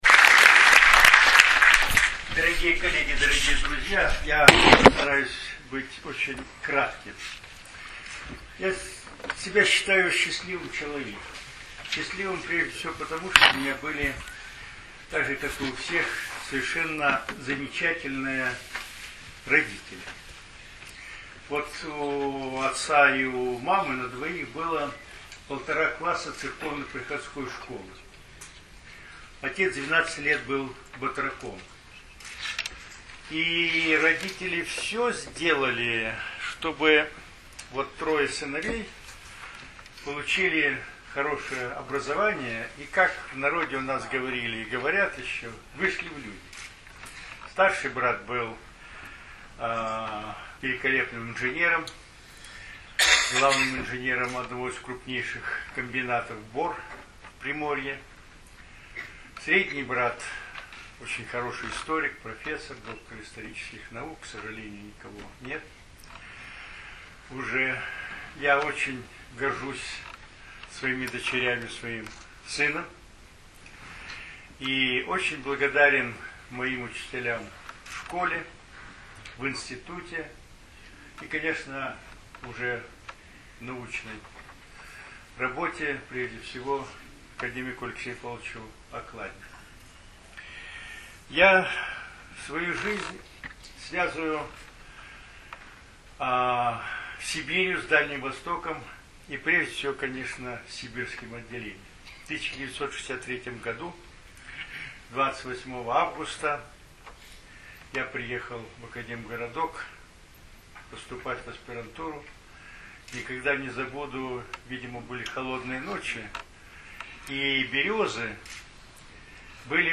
23 января в Доме ученых прошло торжественное мероприятие, посвященное 70-летию выдающегося археолога академика Анатолия Пантелеевича Деревянко. Предлагаем вашему вниманию аудиозапись выступления юбиляра и сотрудника Института эволюционной антропологии общества Макса Планка одного из основателей палеогенетики Сванте Паабо.